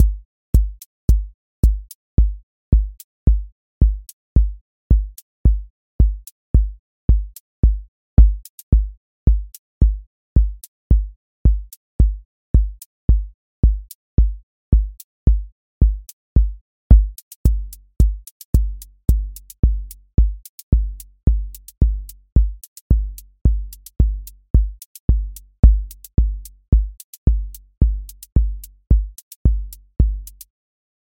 QA Test — Four on Floor
Four on Floor QA Listening Test house Template: four_on_floor April 18, 2026 ← Back to all listening tests Audio Four on Floor
voice_kick_808 voice_hat_rimshot voice_sub_pulse